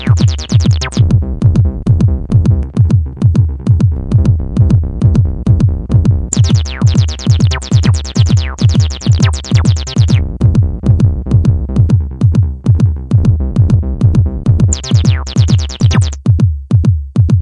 鼓 贝斯 吉他 " 贝斯合成器
描述：低音合成音是由FL工作室创作的
Tag: 低音 循环 合成器 SubBass会 吉他 背景